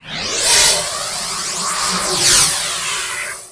lock2.wav